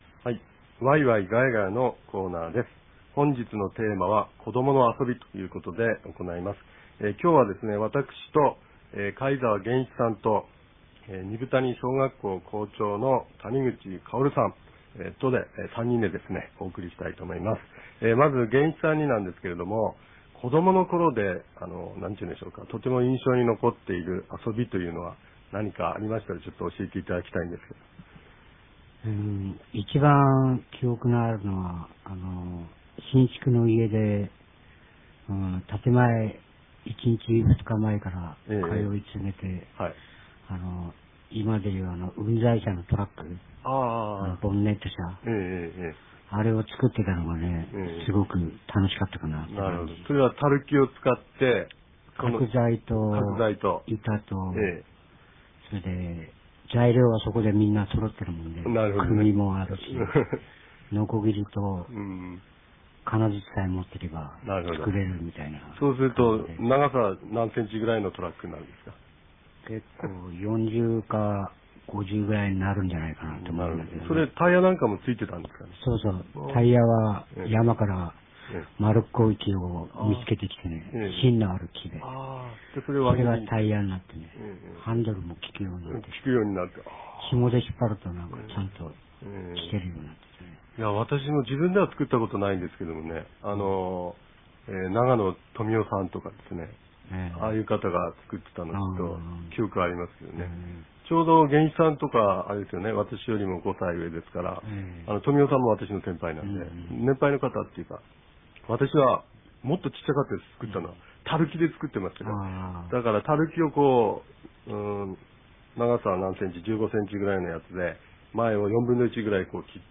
■オープニング「ヤイト゜レンペコイキ」
地域のニュース